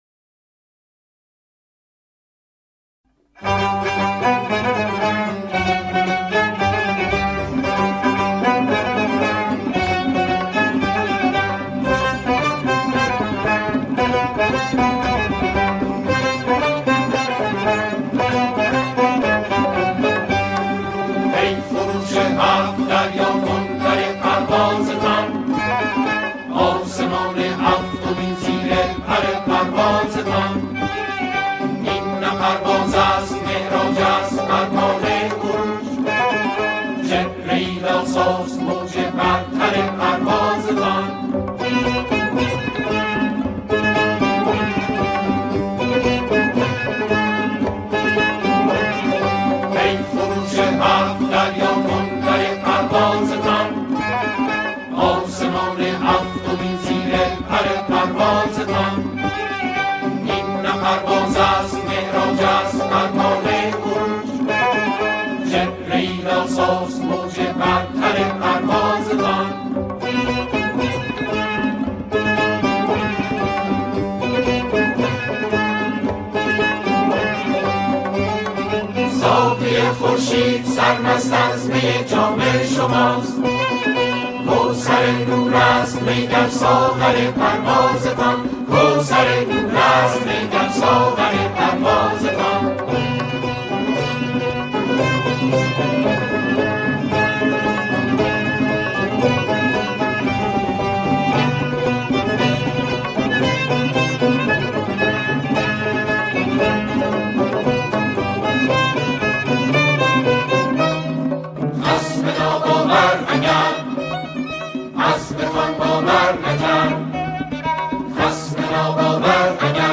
سرودهای شهدا
با صدای گروهی از جمعخوانان